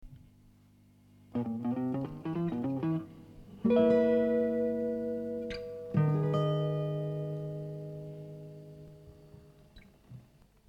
1995年　Ｍｉｌａｎｏ